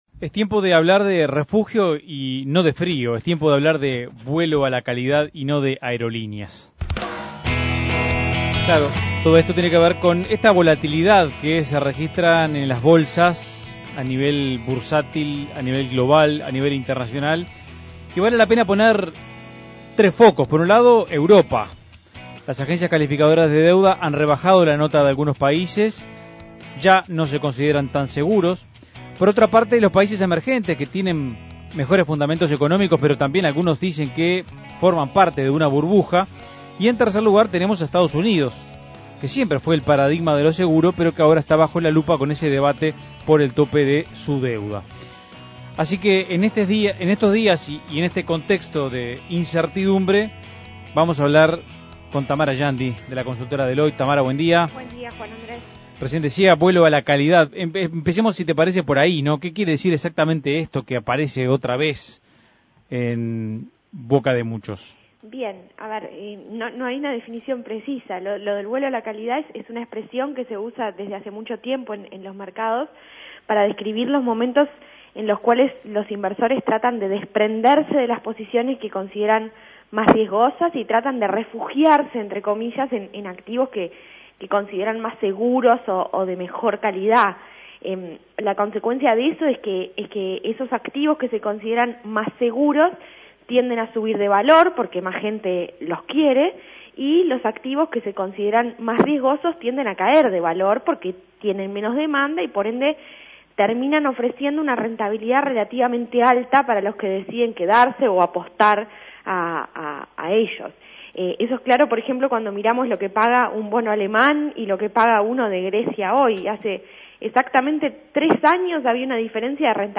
Análisis Económico ¿Qué activos pueden operar como "refugio" para los inversores en un contexto internacional cada vez más incierto?